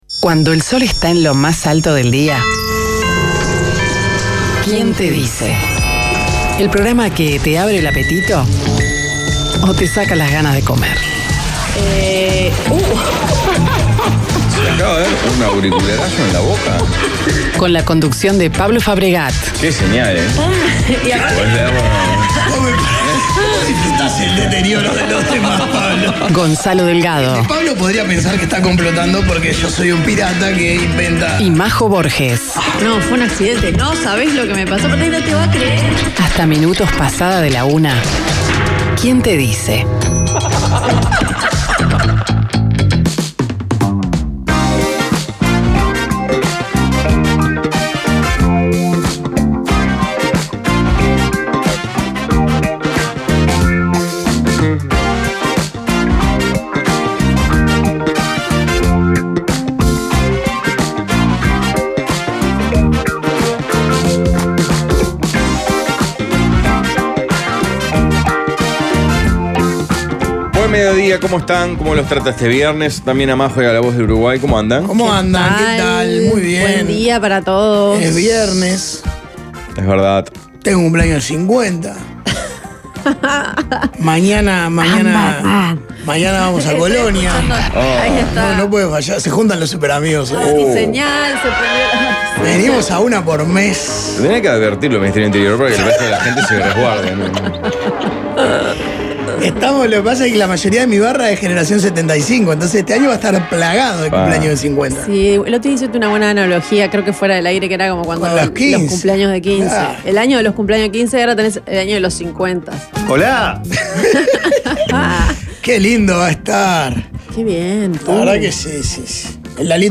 Entrevistas # Humor